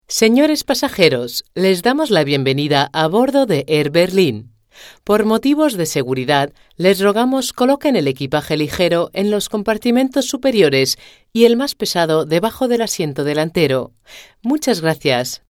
Profi-Sprecherin spanisch.
Sprechprobe: eLearning (Muttersprache):
spanish female voice over artist.